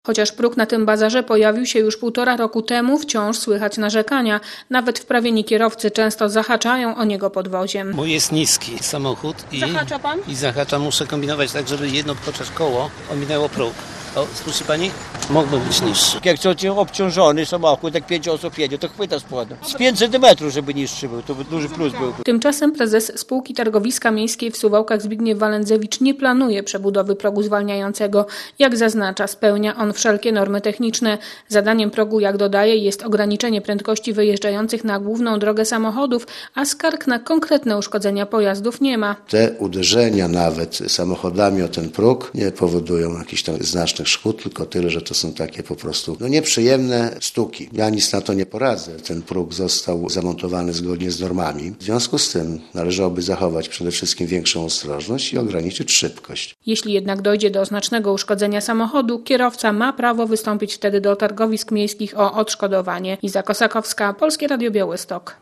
Próg zwalniający przed suwalskim targowiskiem niszczy samochody - relacja